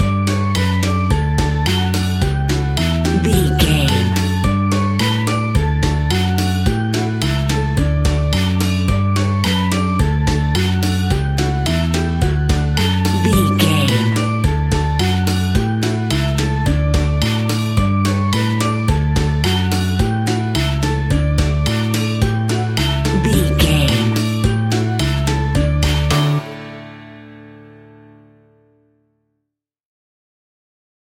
Ionian/Major
D
Fast
kids instrumentals
childlike
cute
kids piano